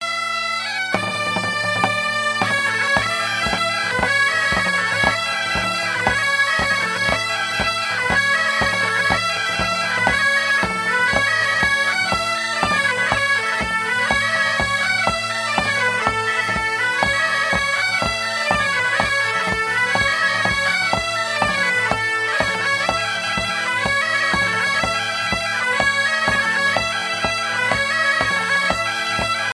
gestuel : à marcher
Genre énumérative
Pièce musicale éditée